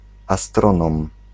Ääntäminen
IPA : /ə.ˈstɹɑn.ə.mɚ/